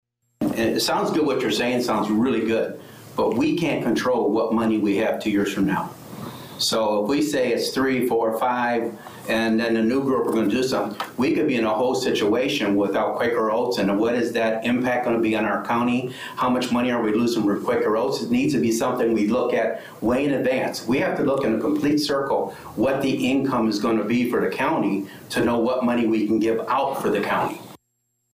County Board member Jim McMahon reacted to Quick’s proposal…